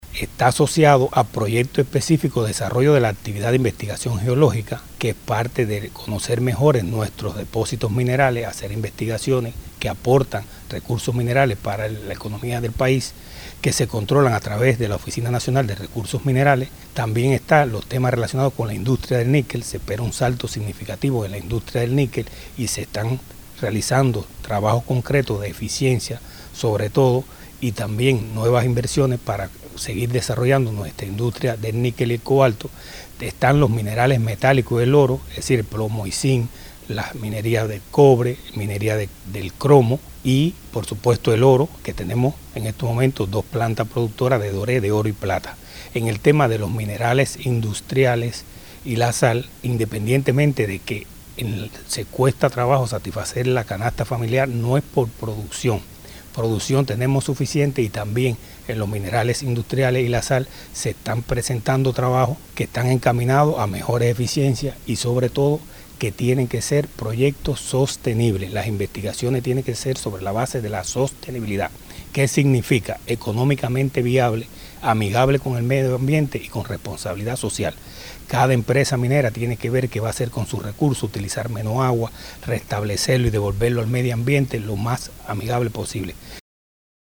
Doctor en Ciencias, Juan Ruiz Quintana, director general de Minería del Ministerio de Energía y Minas.
Declaraciones-del-director-de-Minas-.mp3